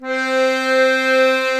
C4 ACCORDI-R.wav